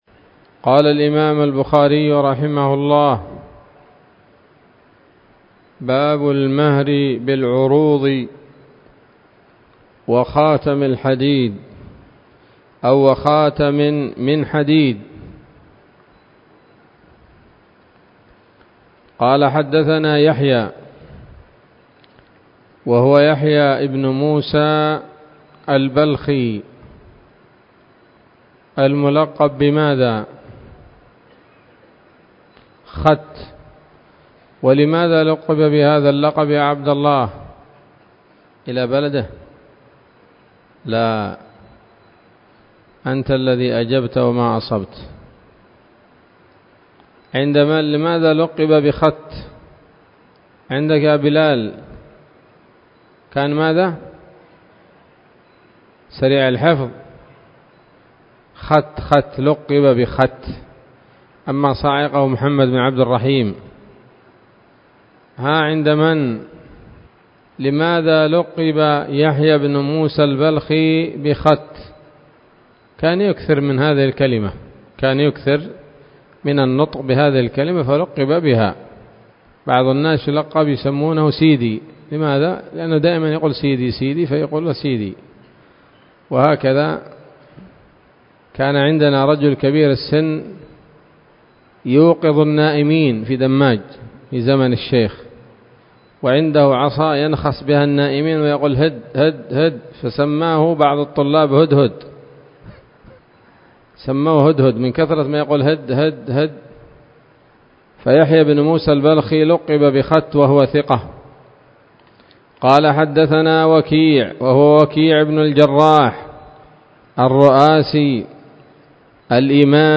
الدرس السادس والأربعون من كتاب النكاح من صحيح الإمام البخاري